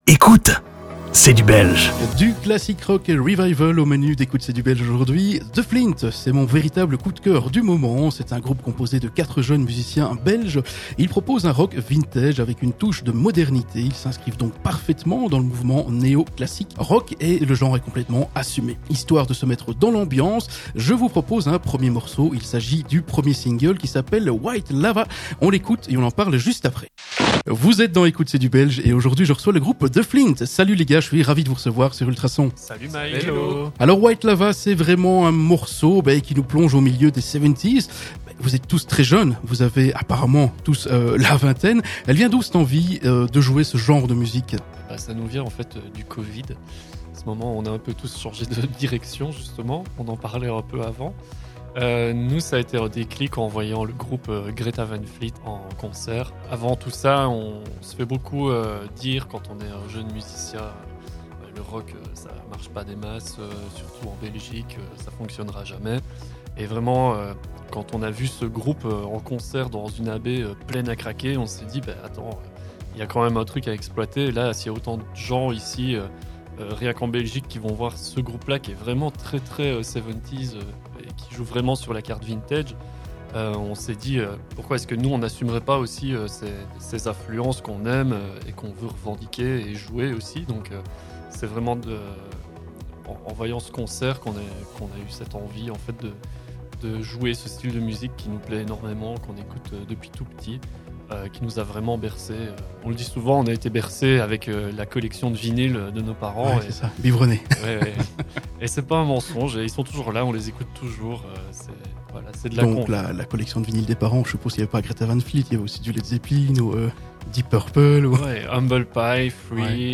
Ecoutez cette interview et vous saurez tout, ou presque, sur ce jeune groupe émergent de la scène musicale belge promis à un bel avenir.